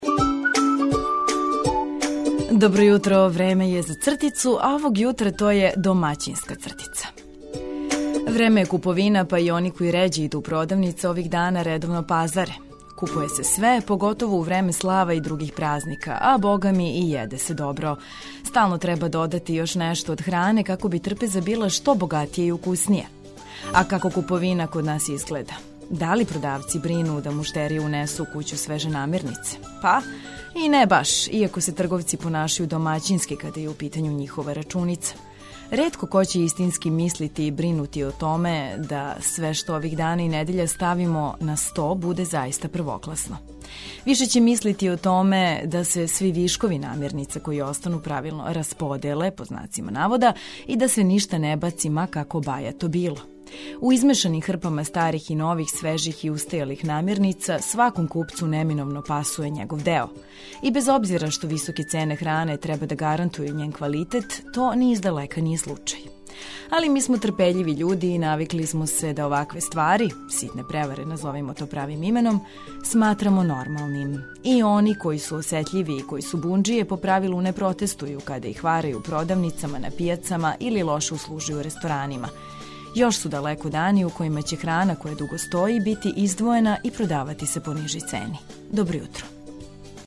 Ново децембарско јутро уз Београд 202, расположену екипу и ведру музику за ведар дан.